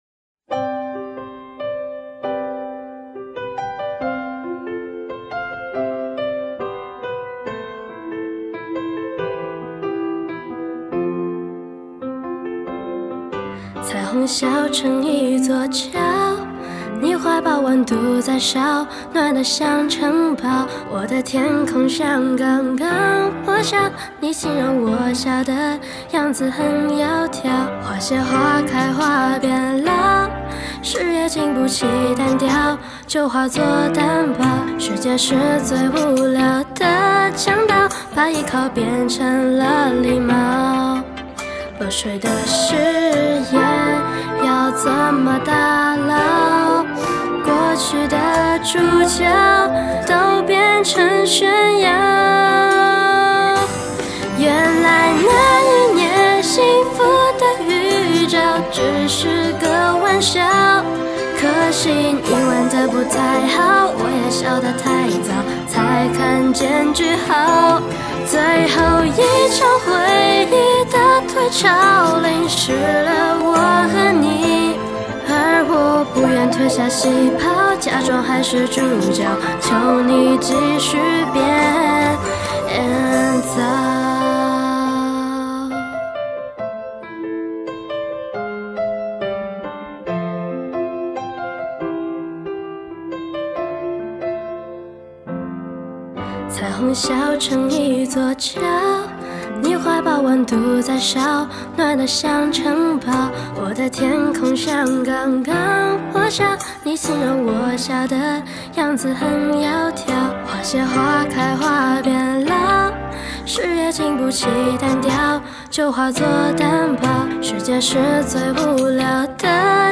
曲风：流行